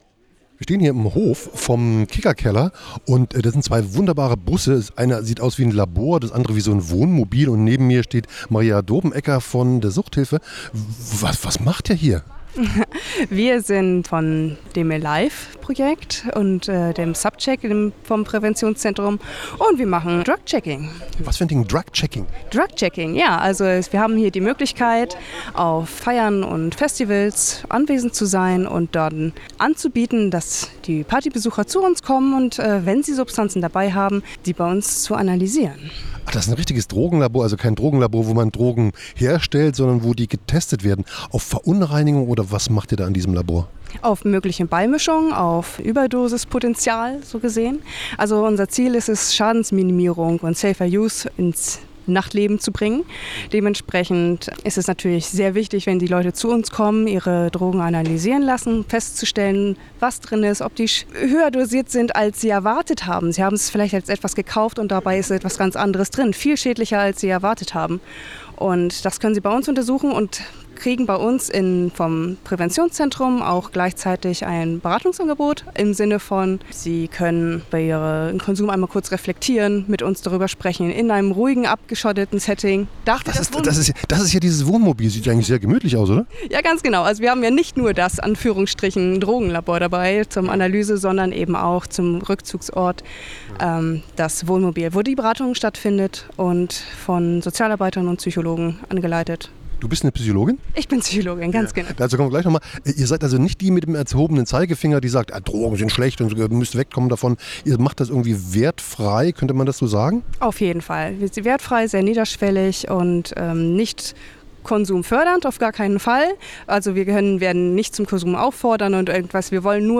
Drogen testen und rumfahren - Tiere und Substanzen I Ein Hinterhofgespräch